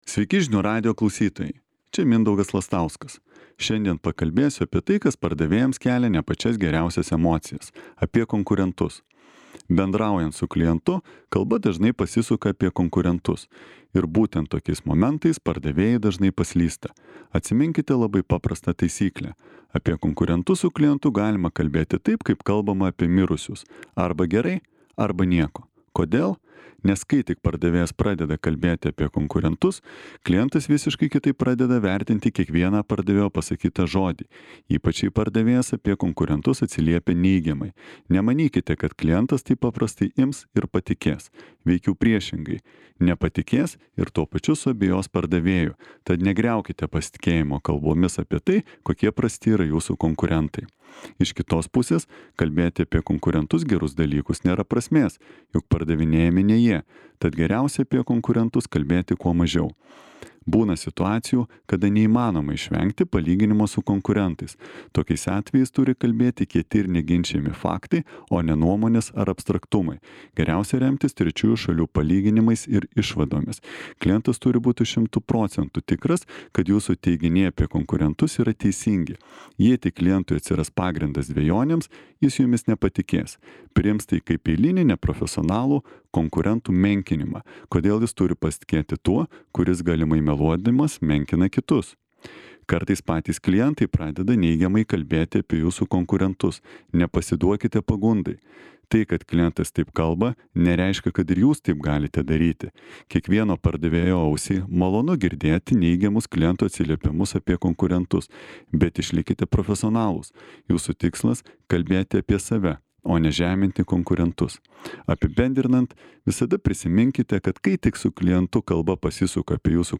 komentaras